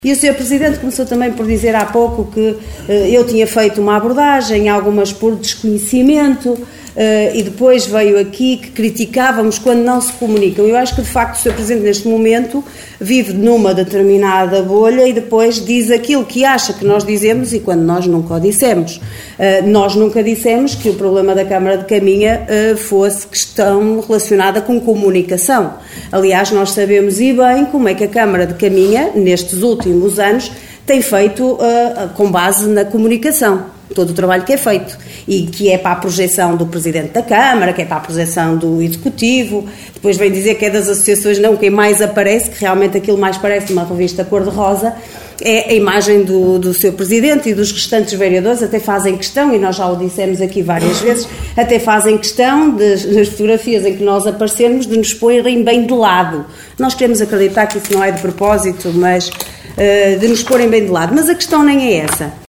Excertos da última reunião de Câmara, realizada na passada quarta-feira no Salão Nobre dos Paços do Concelho, para contratação de 10 funcionários e atribuição de subsídios.